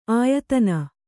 ♪ āyatatna